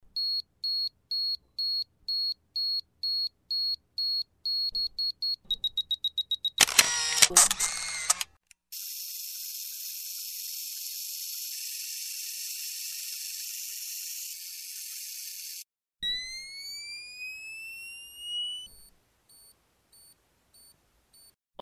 Âm thanh rất phổ biến trong cuộc sống hàng ngày này vang lên cạch một tiếng thông báo cho người dùng biết điện thoại đã được mở khóa.